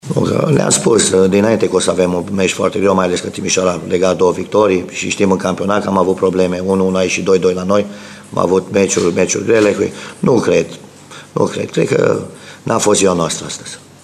În schimb, Vasile Miriuță, antrenorul clujenilor, a spus că rezultatul a fost un accident și a promis că echipa va arăta altfel în campionat:
Miriuta-nu-am-desconsiderat-Timisoara.mp3